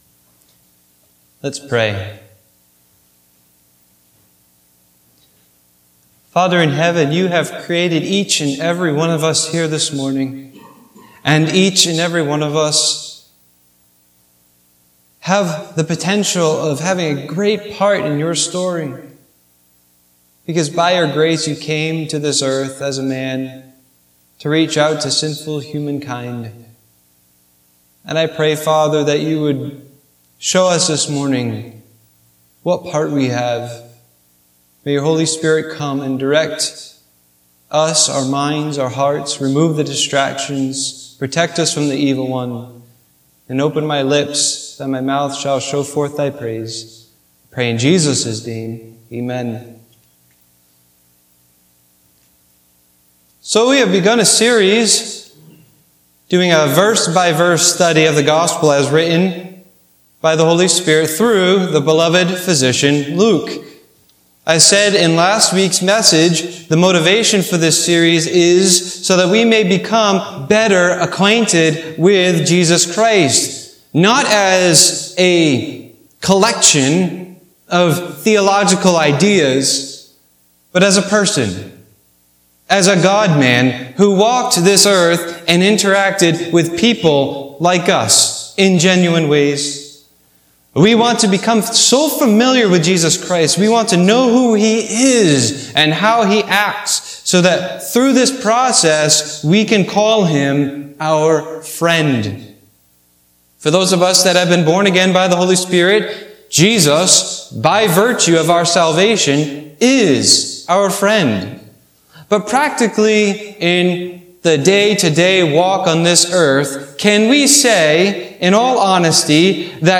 Passage: Luke 1:5-25 Service Type: Sunday Morning Worship